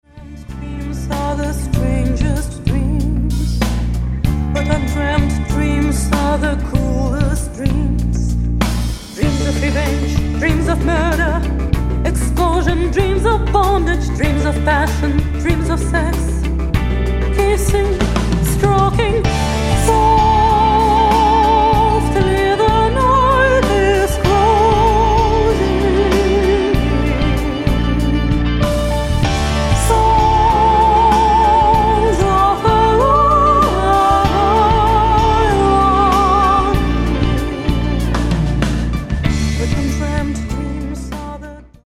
Backup vocals
Bass guitar
Drums